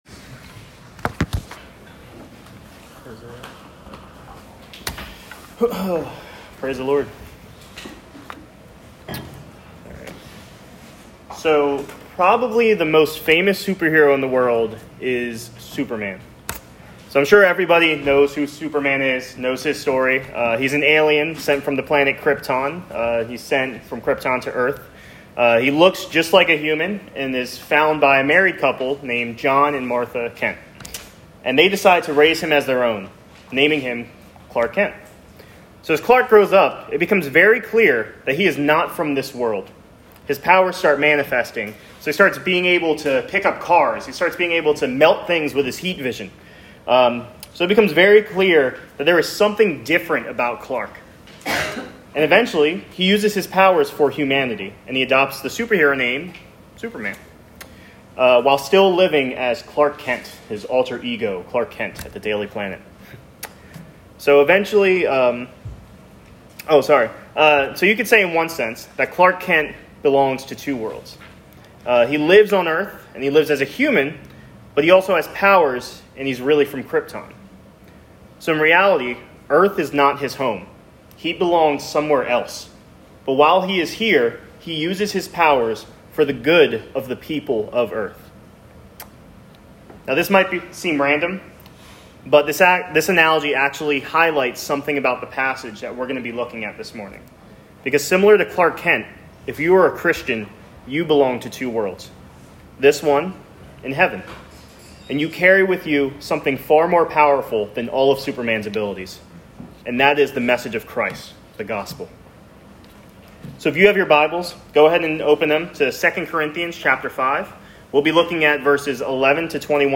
All Sermons “Be His Ambassadors”